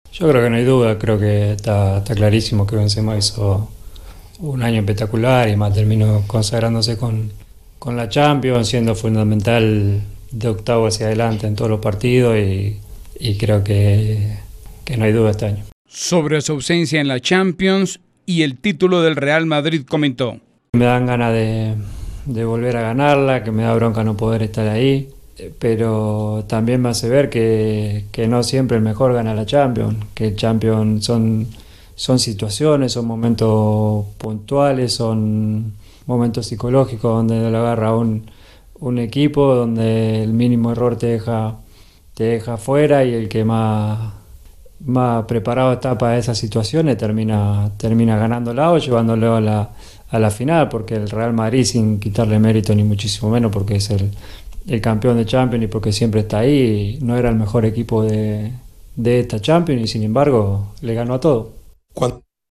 (Lionel Messi en entreista con TyC Sports)